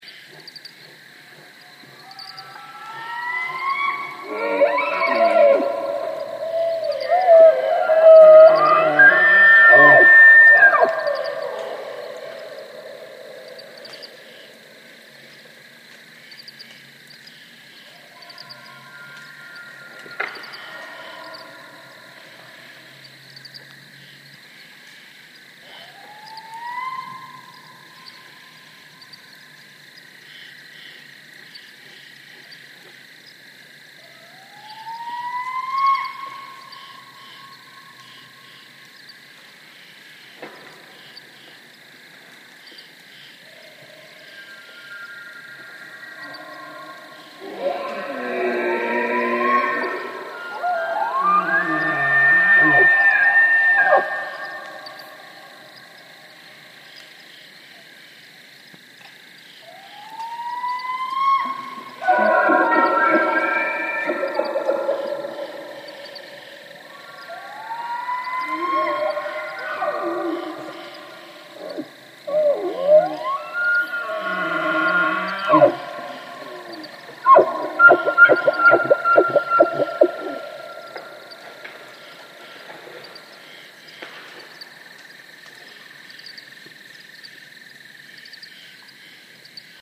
a Barred Owl responding to elk bugling is the closest thing I could find to the sound I heard that night.  The elk (which don’t live in our neighbourhood) are pretty obvious and the Barred Owl is the scream in the background.